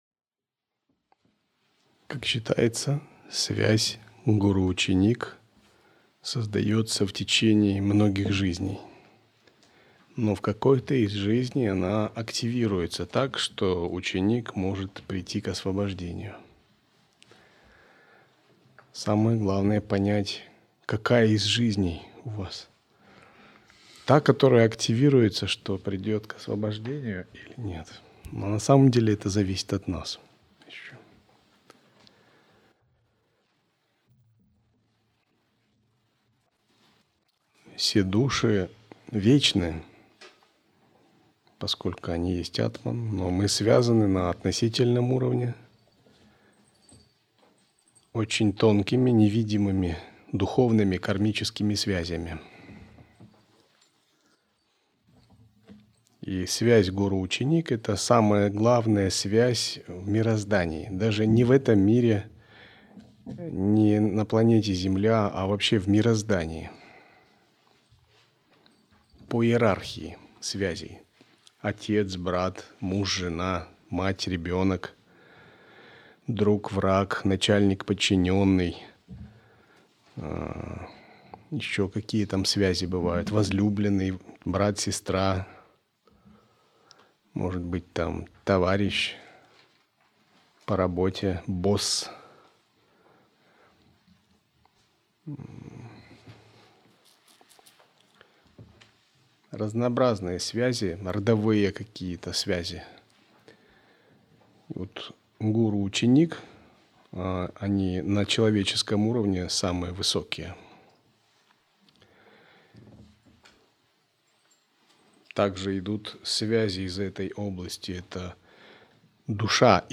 Аудиолекции